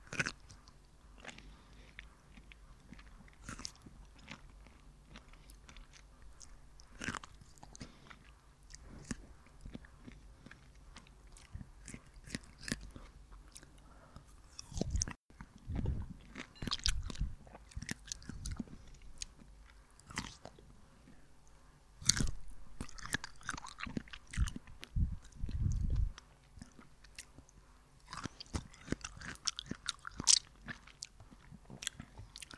Звук поїдання свіжого ананаса